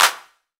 9CLAP.wav